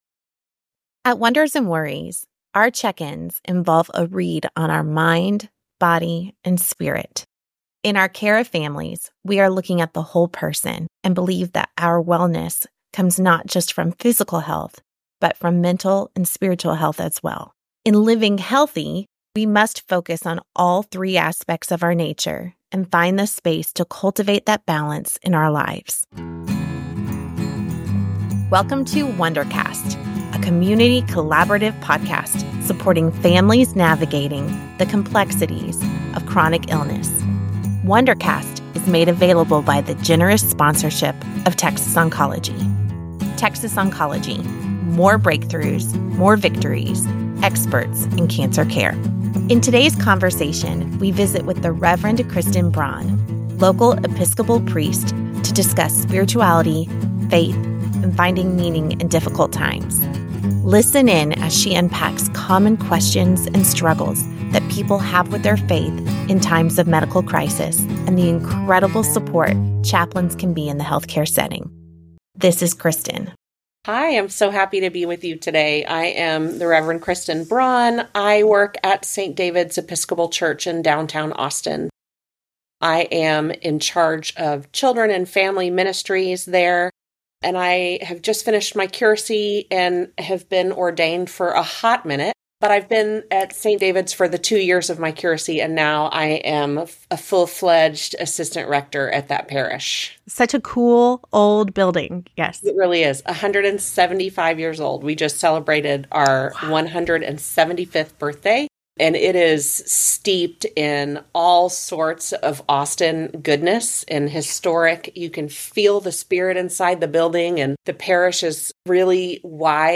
Podcast to discuss the importance of spiritual care and its supportive role in our overall health and wellness. This episode highlights the role of chaplains in hospitals and the holistic care they provide.